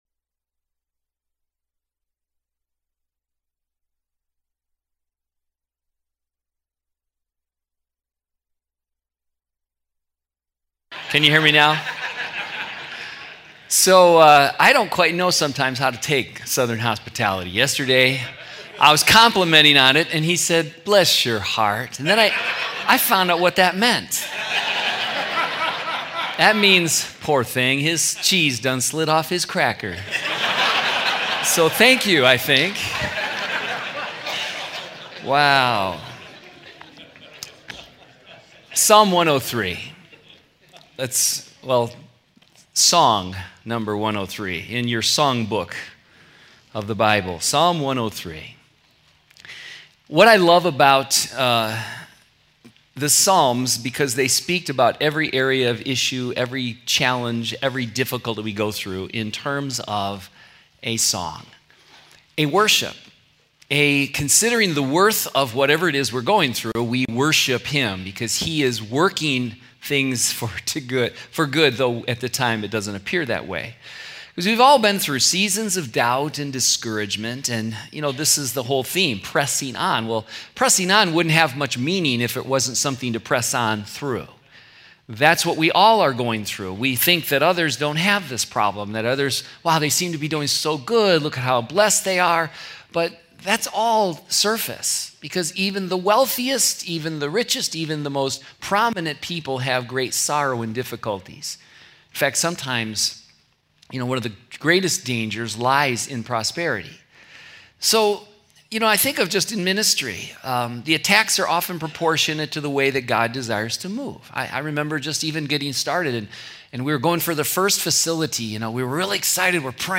2017 Home » Sermons » Session 4 Share Facebook Twitter LinkedIn Email Topics